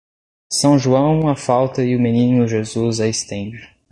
Pronunciado como (IPA)
/ʒoˈɐ̃w̃/